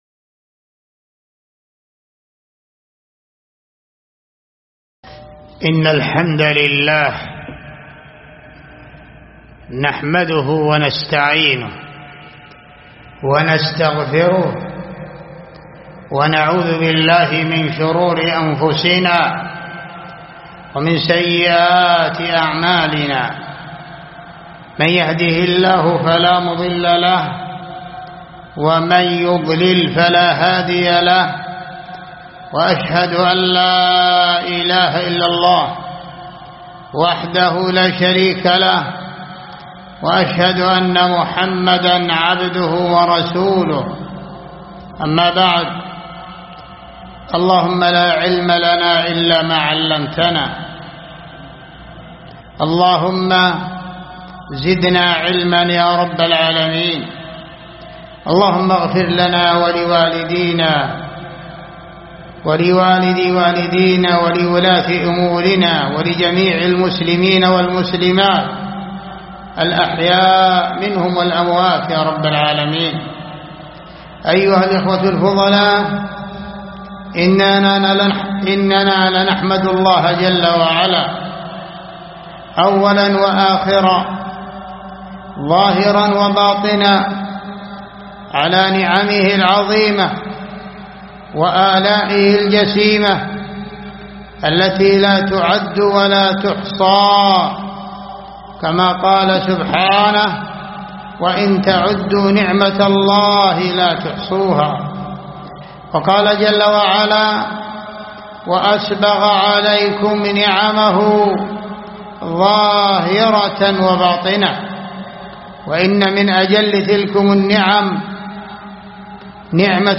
جامع الأوسط بالطوال سماع المحاضرة